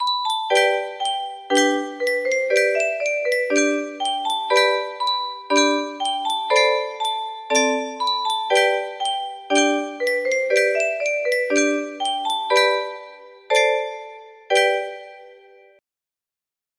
- 30 Keys music box melody